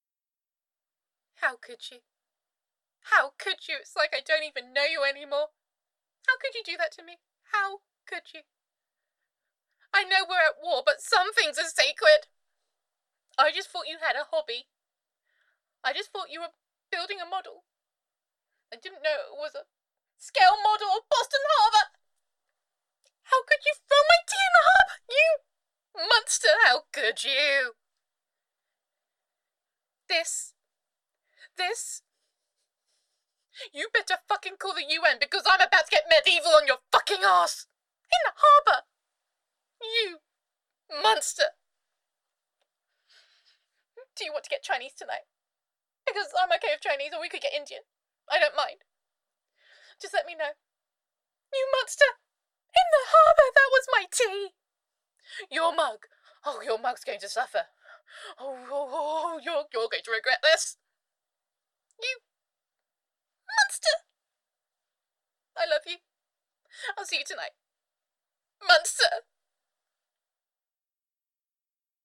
[F4A] Intolerable Cruel-Tea [Teapot War Two][You Monster][Girlfriend Voicemail][Gender Neutral][You Have Gone Too Far This Time]